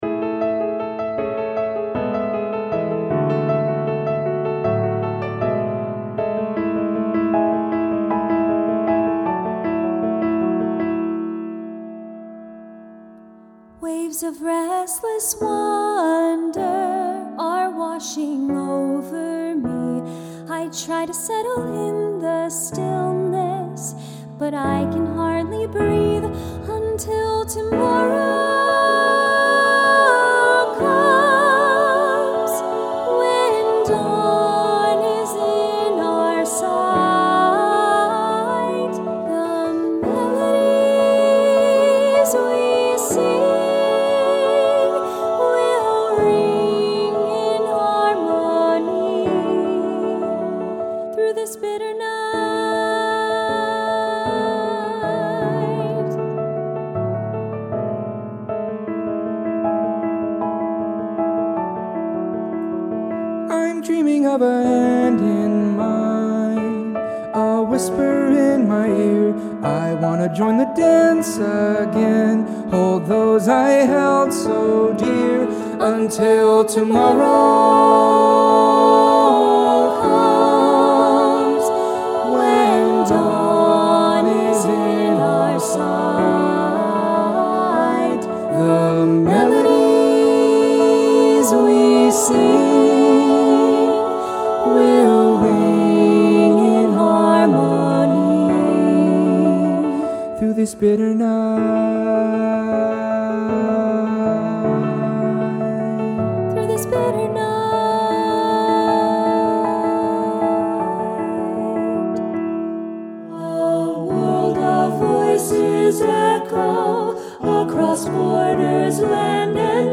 SATB Chorus with divisi, Soli, & Piano